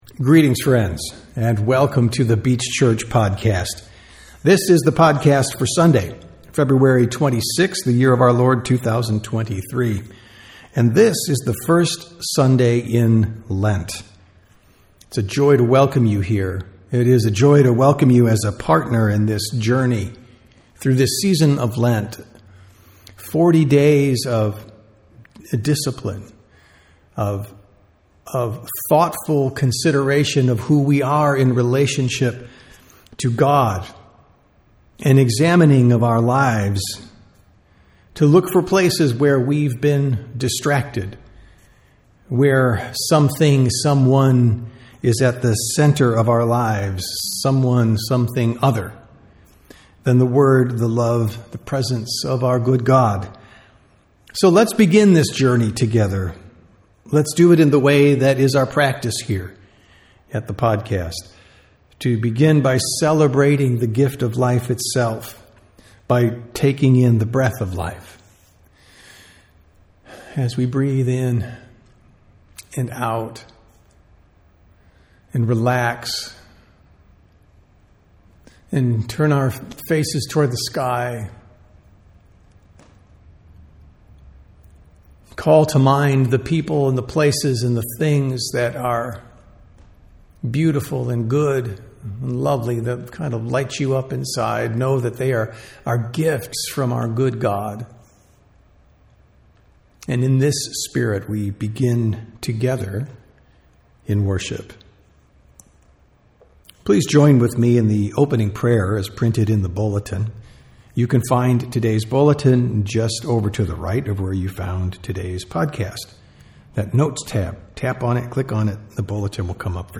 Sermons | The Beach Church